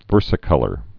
(vûrsĭ-kŭlər) also ver·si·col·ored (-kŭlərd)